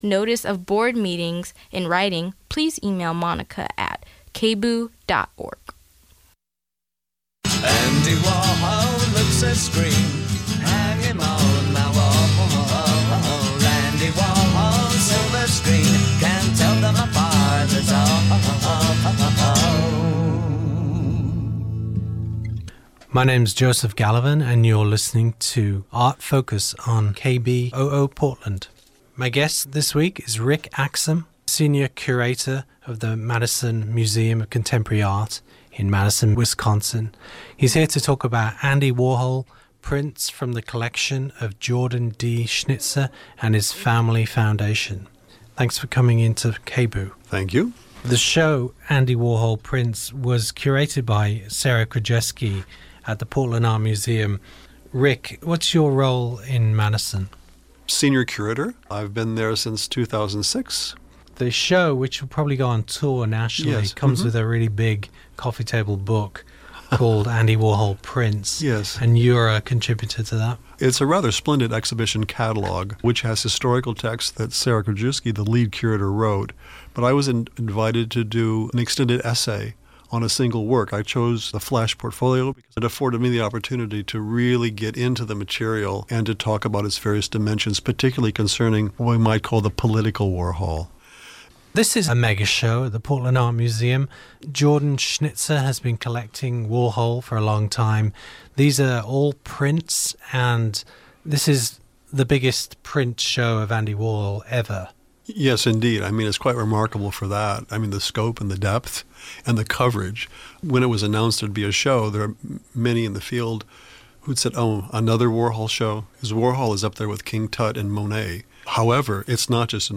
This show was recorded at KBOO on October 7, 2016 in the new Studio 3.